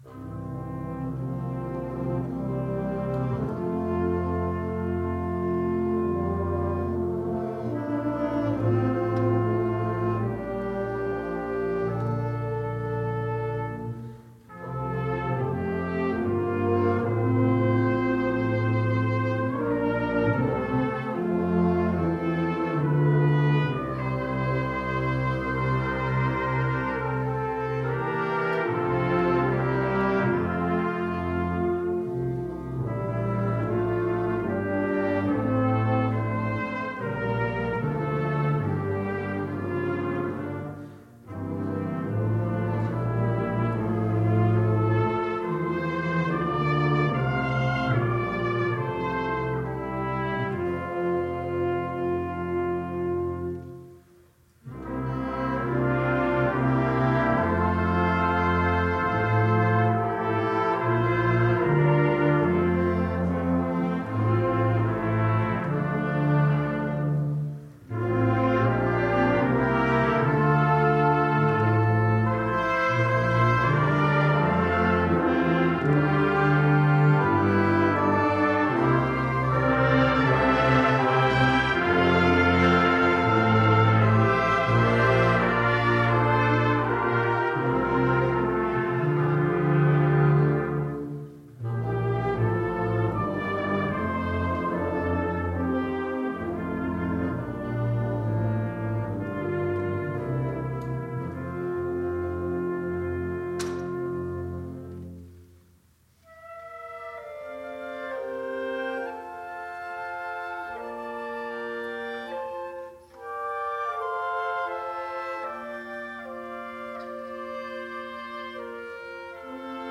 Youth Wind Ensemble of Westchester Fall 2016
Youth Wind Ensemble of Westchester Fall Concert December 16, 2016